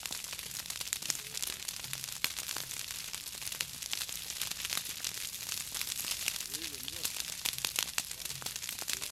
fo_campfire_crackle_01_hpx
Campfire crackling and popping. Fire, Crackle Popping, Fire . Fire Burn.